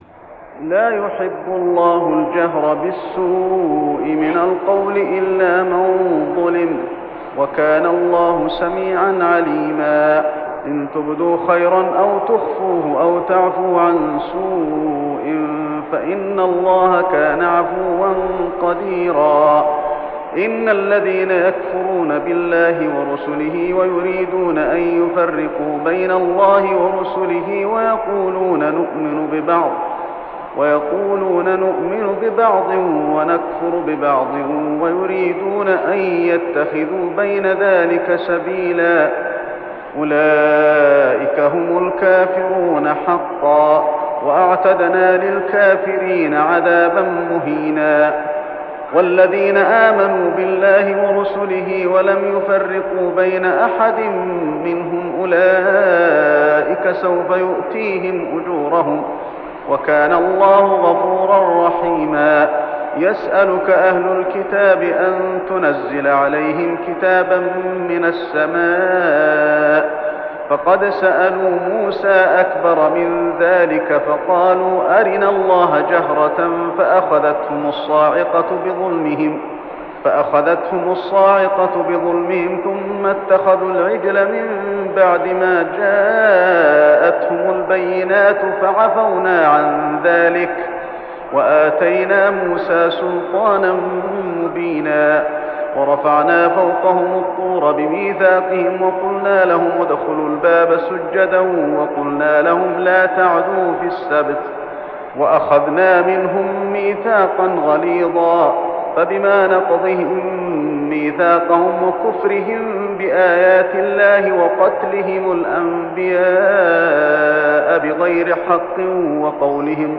صلاة التراويح ليلة 6-9-1408هـ سورتي النساء 148-176 و المائدة 1-81 | Tarawih prayer Surah An-Nisa and Al-Ma'idah > تراويح الحرم المكي عام 1408 🕋 > التراويح - تلاوات الحرمين